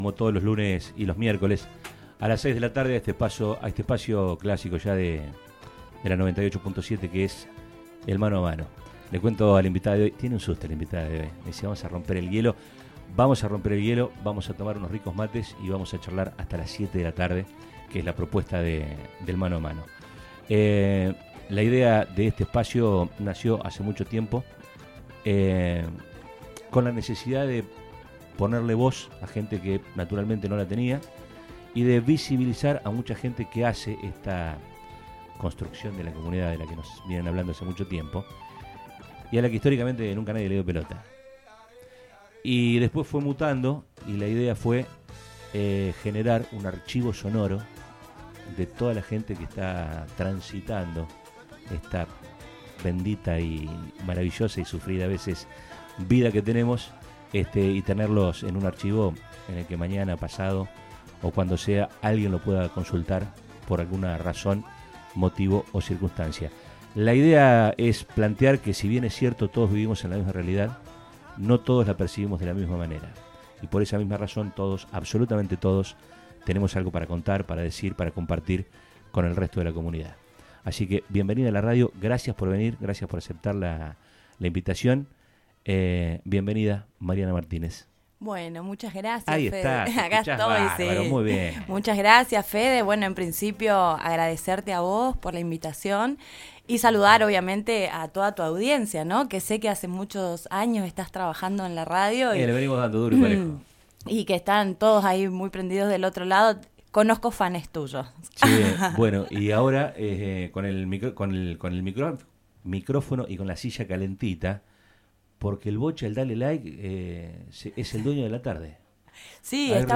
A mediados del mes de abril, comenzamos el Ciclo de entrevistas “Mano a mano”, temporada 2025.